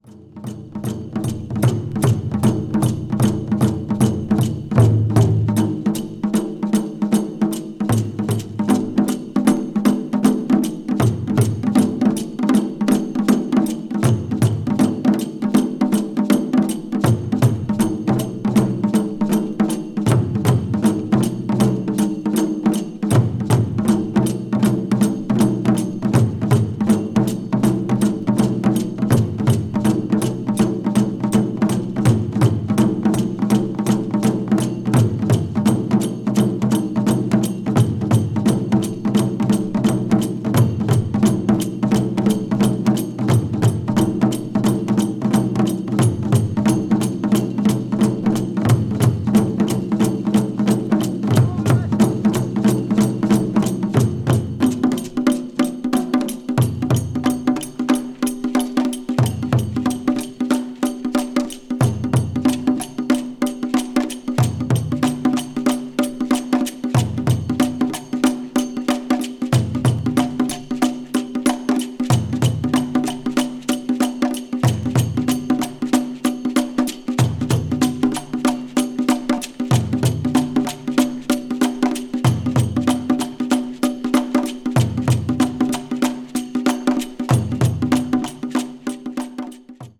media : EX+/EX+(some slightly noises.)
particularly powerful